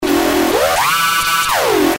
标签： 循环 沙哑 怪异 小丑 邪恶 恐怖 大规模
声道立体声